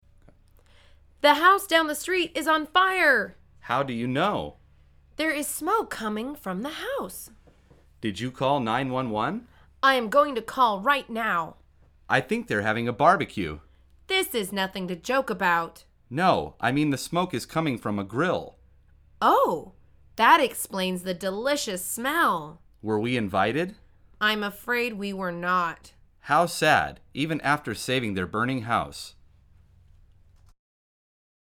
مجموعه مکالمات ساده و آسان انگلیسی – درس شماره هفتم از فصل مسکن: باربیکیو تو فضای باز